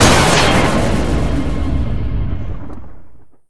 deadyey_shot_01.wav